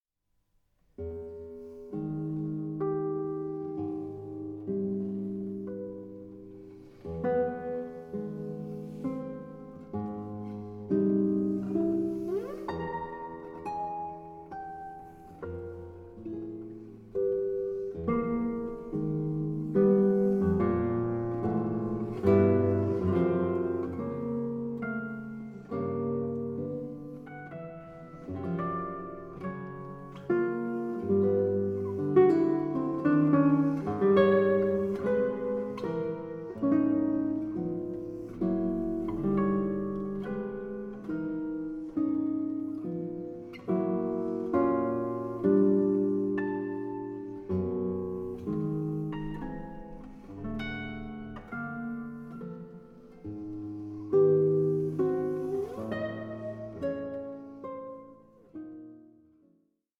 SOLO GUITAR MASTERPIECE
Guitarist
opiate-induced